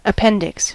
appendix: Wikimedia Commons US English Pronunciations
En-us-appendix.WAV